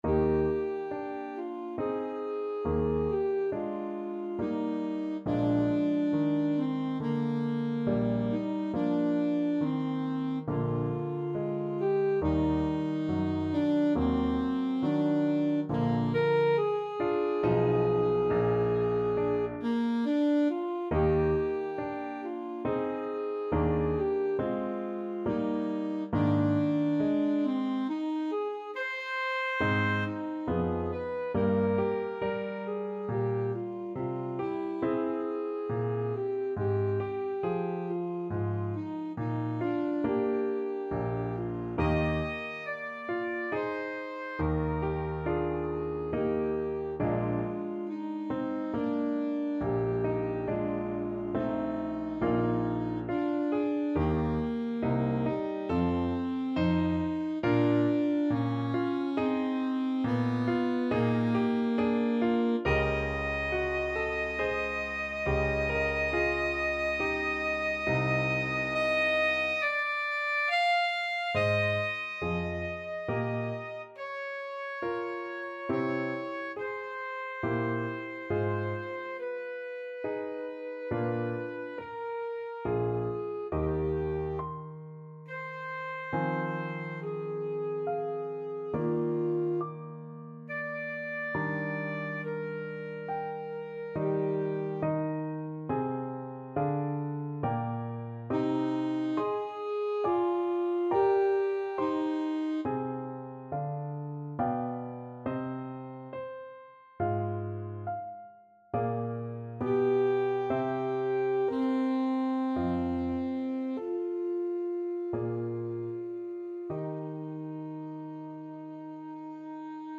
Classical Brahms, Johannes Piano Concerto No.2, Op. 83, Slow Movement Main Theme Alto Saxophone version
Alto Saxophone
6/4 (View more 6/4 Music)
Andante =c.84 =69
G4-F6
Eb major (Sounding Pitch) C major (Alto Saxophone in Eb) (View more Eb major Music for Saxophone )
Classical (View more Classical Saxophone Music)